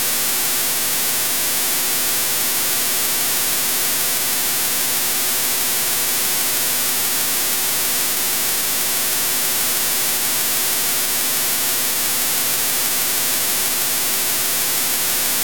Recording of first 20KHz of upper digital sideband of China Digital Radio.